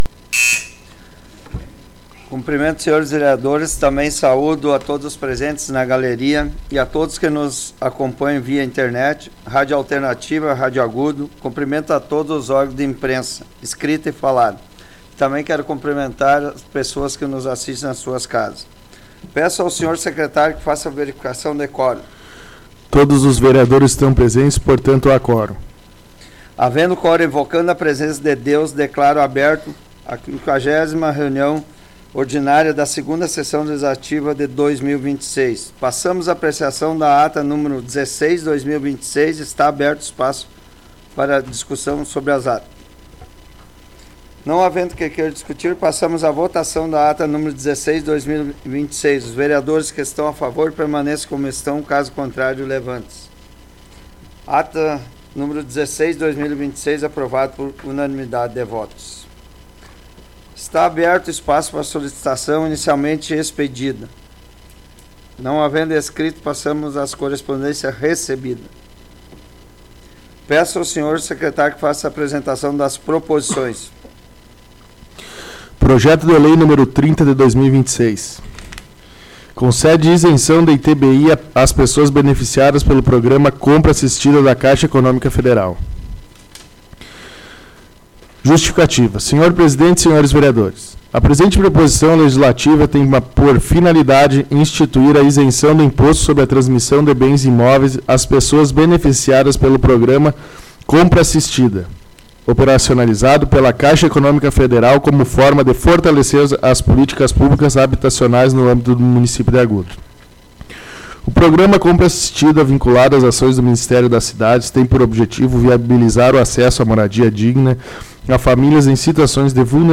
Áudio da 50ª Sessão Plenária Ordinária da 17ª Legislatura, de 06 de abril de 2026.